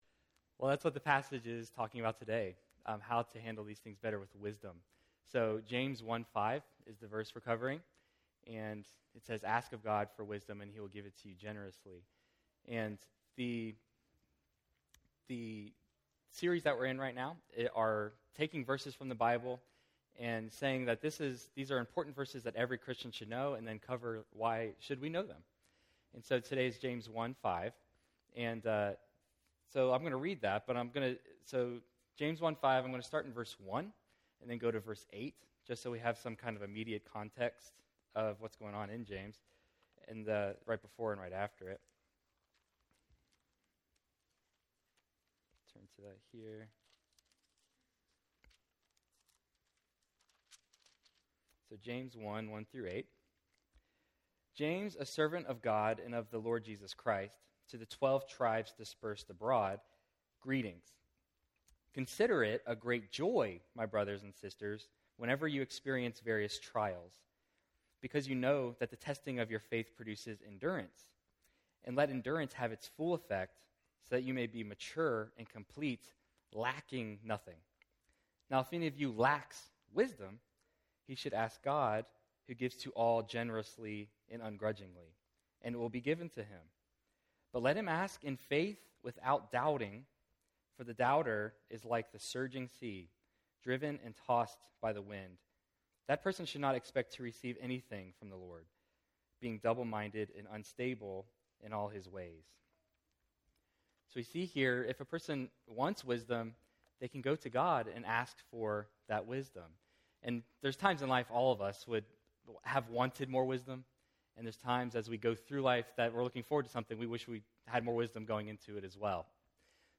Life is full of choices, challenges, and moments that leave us wondering what to do next. In this sermon, we’ll discover how God offers practical wisdom to anyone who asks.